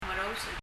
omerous　　　[ɔmɛraus]
発音